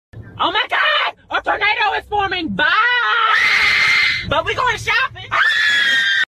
oh-my-god-a-tornado-is-forming-made-with-Voicemod-technology.mp3